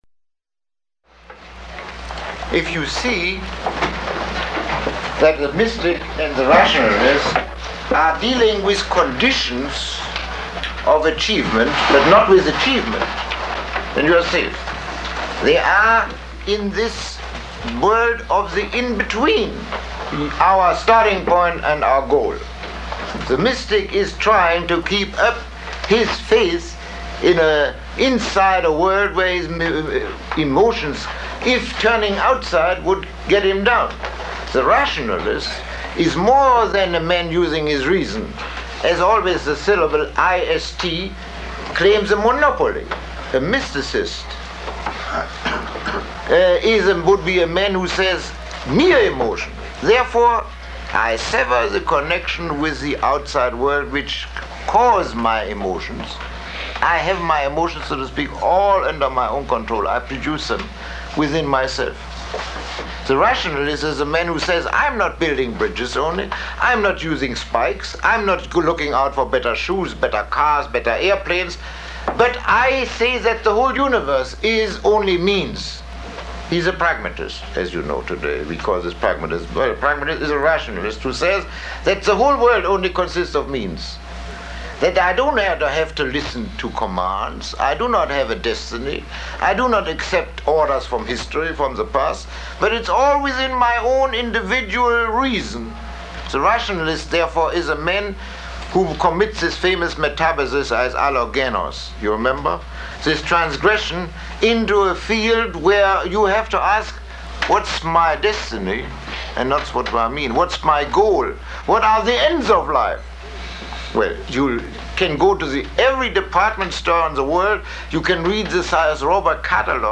Lecture 17